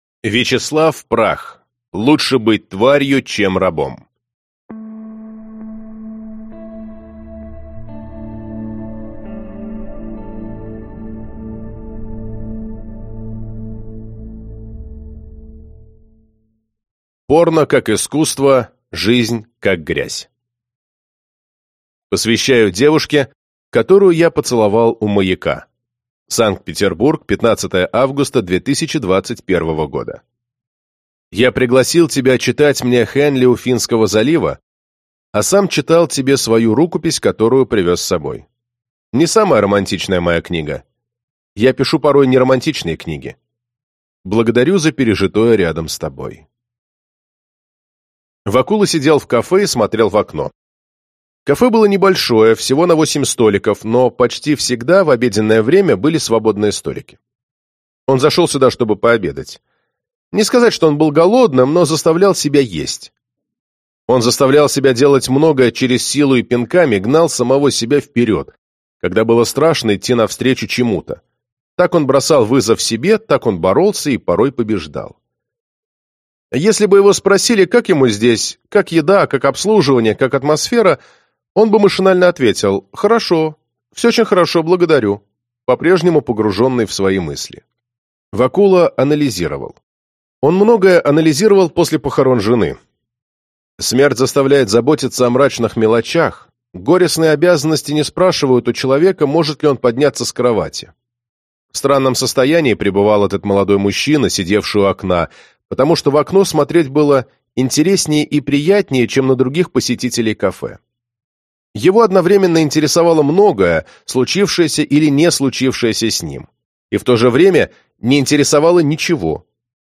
Аудиокнига Лучше быть тварью, чем рабом | Библиотека аудиокниг